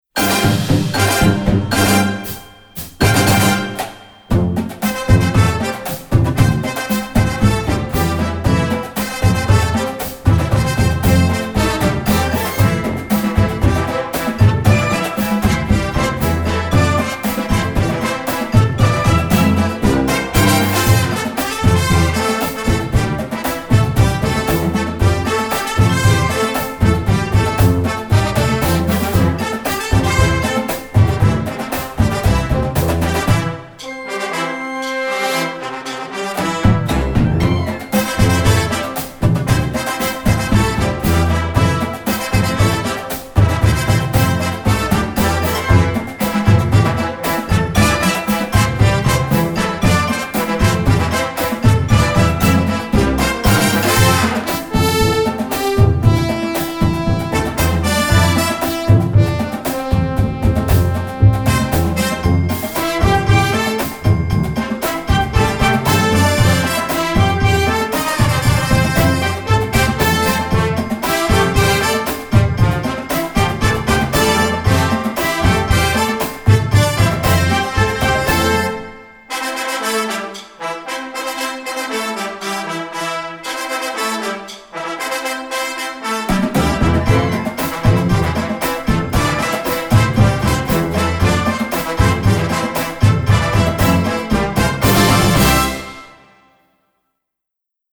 1ST BB TRUMPET 8 1
SNARE DRUM 8 1
Series: Contemporary Marching Band